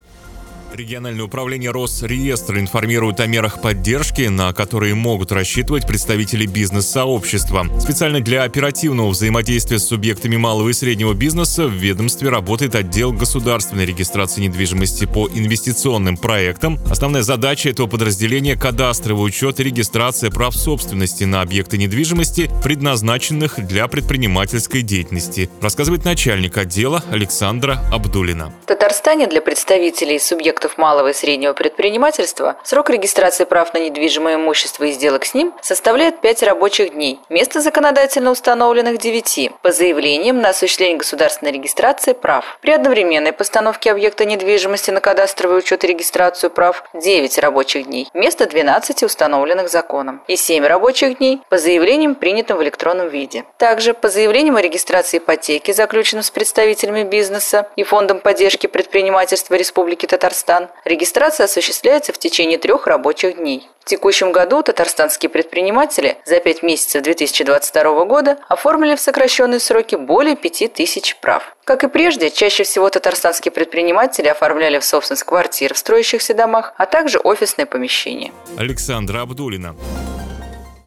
Аудиорепортаж: